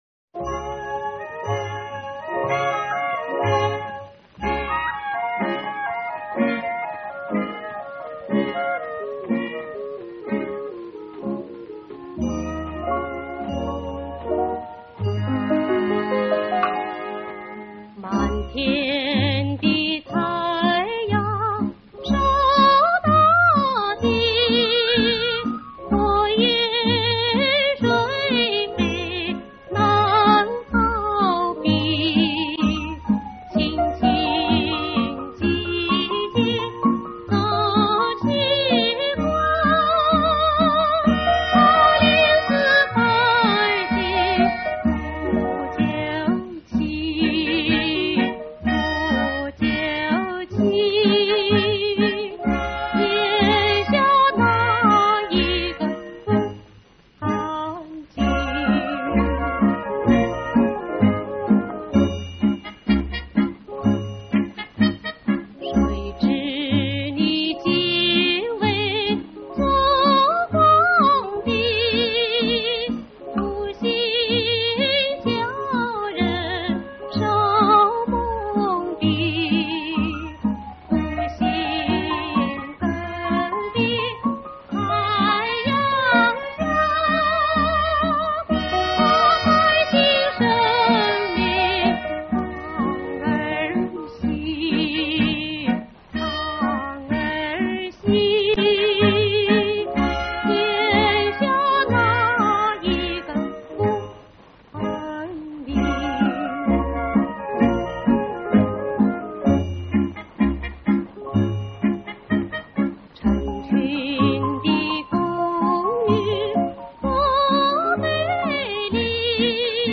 音乐类别:  [流行] [民族]